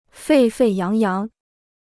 沸沸扬扬 (fèi fèi yáng yáng) Free Chinese Dictionary